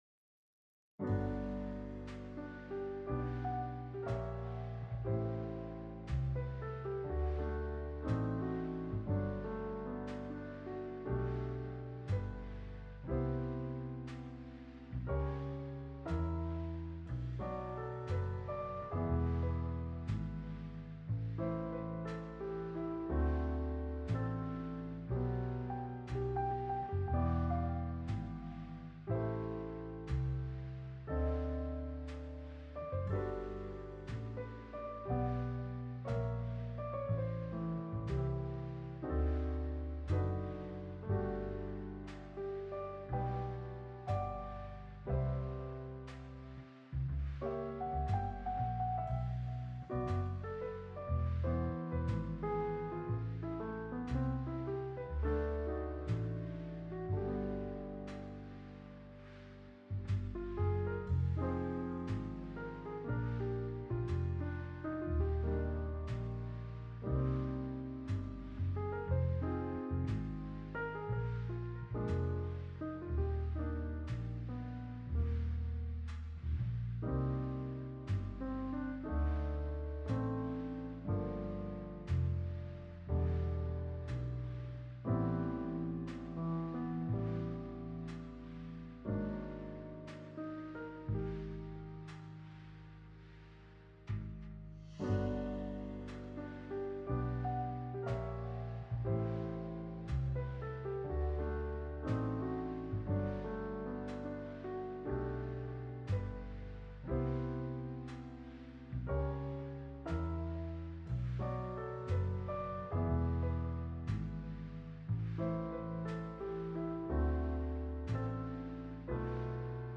勉強BGM